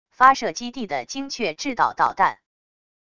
发射基地的精确制导导弹wav音频